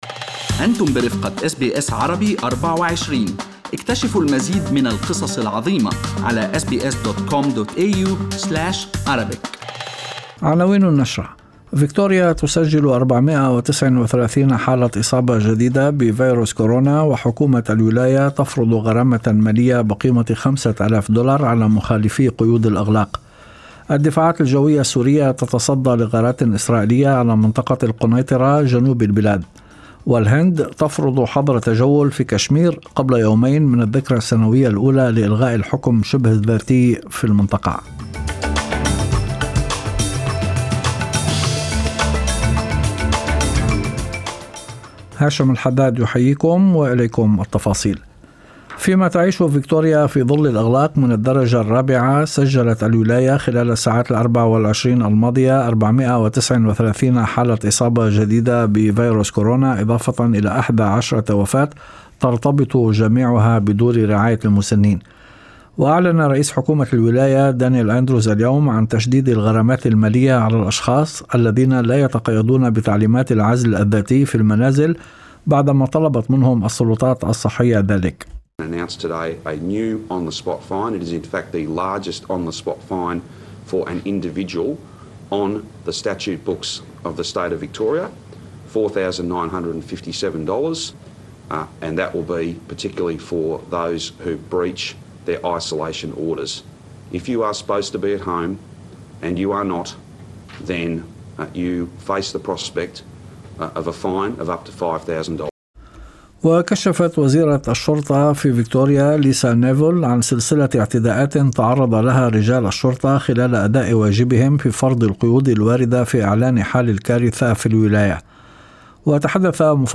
نشرة أخبار المساء 4/8/2020